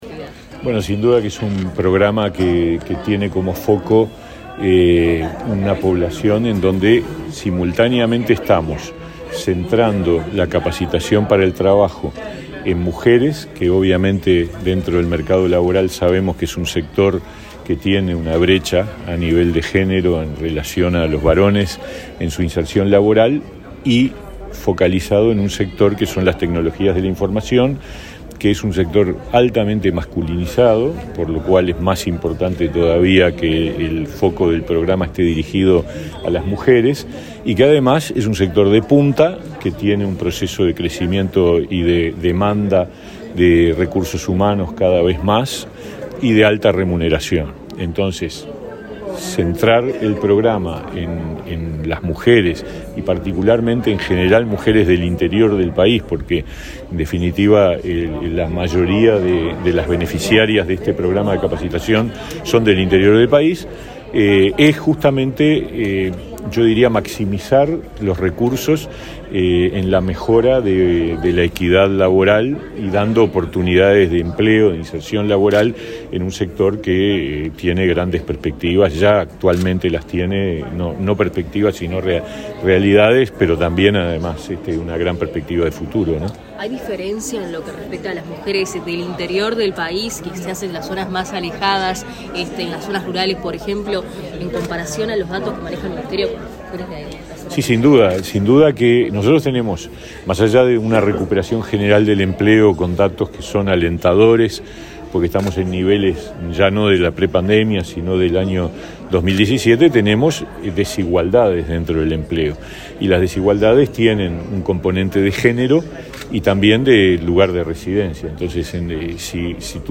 Declaraciones del ministro de Trabajo, Pablo Mieres
El ministro de Trabajo, Pablo Mieres, dialogó con la prensa, este miércoles 11 en Montevideo, antes de participar en un seminario sobre formación